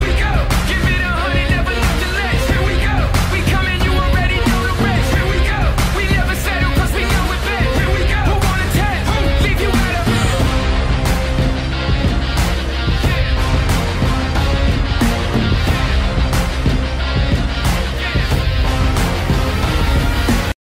Kategorien: Filmmusik